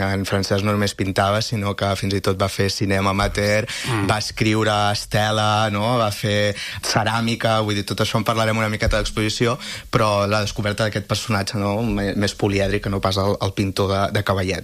en una entrevista a l’FM i +.